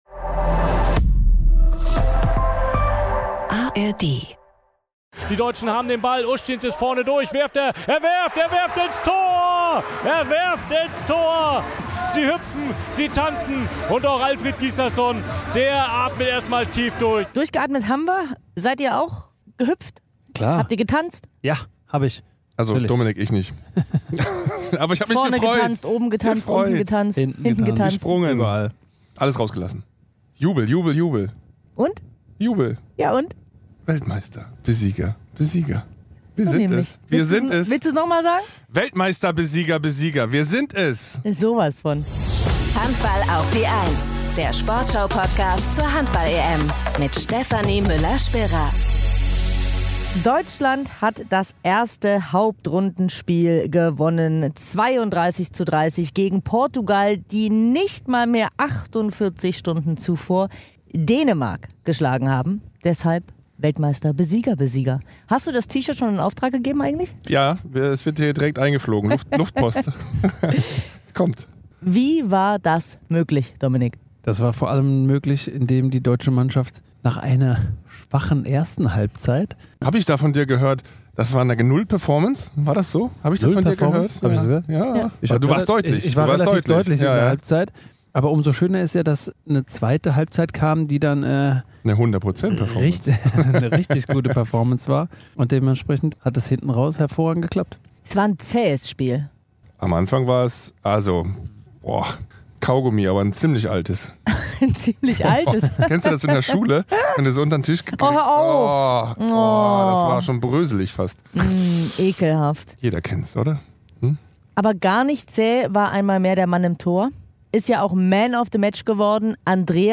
Außerdem hört ihr alle Stimmen zum Spiel.